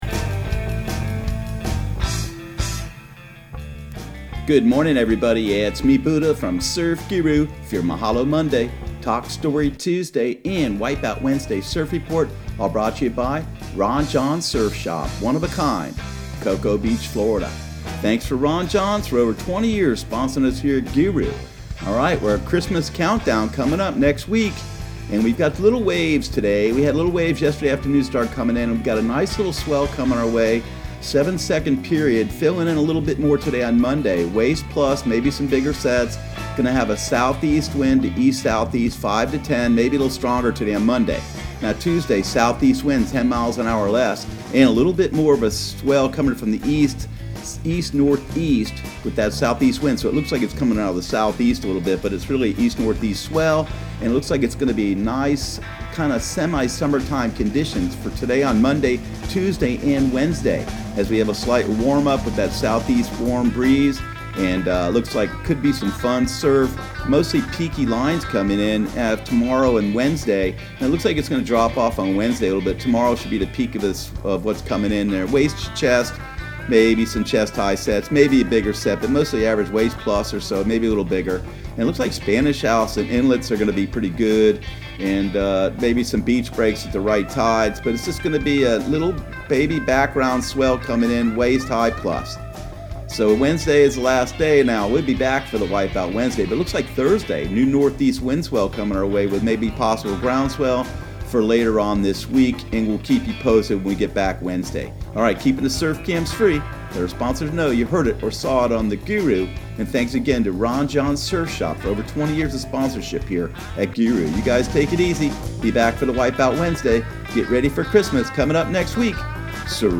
Surf Guru Surf Report and Forecast 12/09/2019 Audio surf report and surf forecast on December 09 for Central Florida and the Southeast.